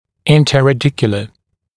[ˌɪntəræ’dɪkjələ][ˌинтэрэ’дикйэлэ]межкорневой, межкорешковый